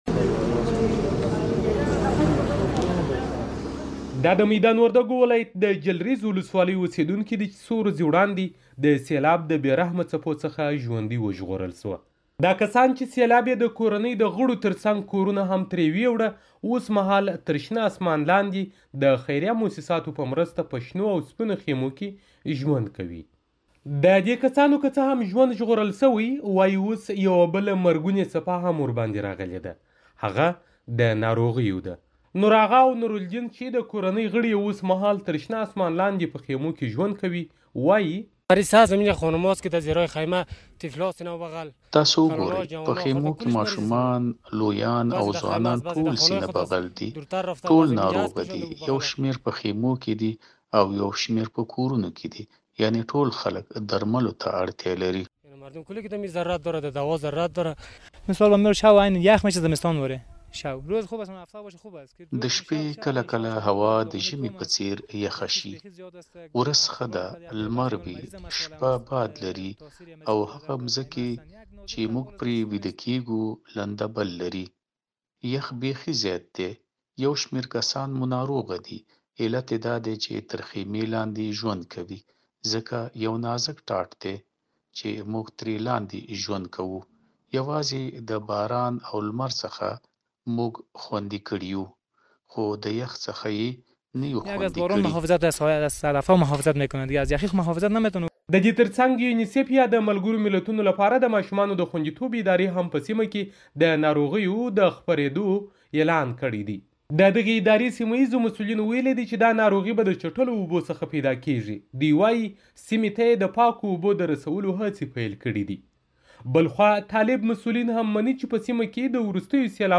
د وردګو راپور